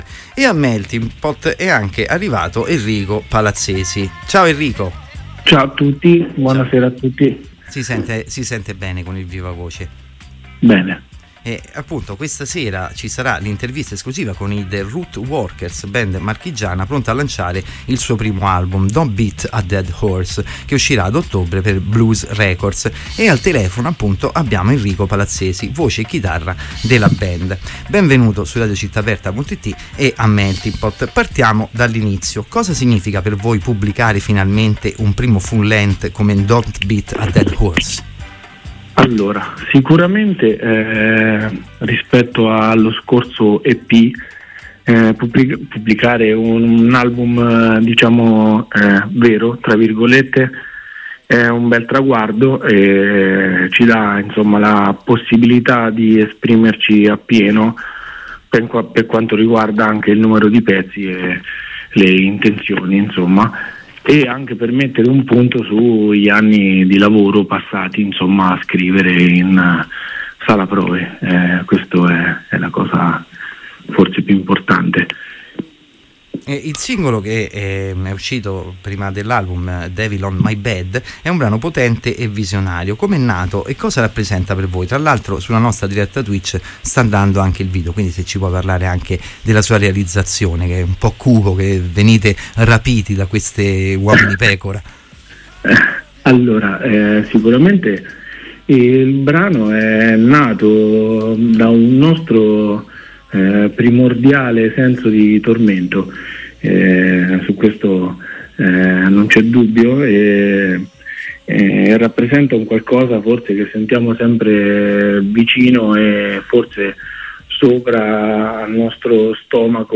Intervista-The-Rootworkers.mp3